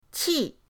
qi4.mp3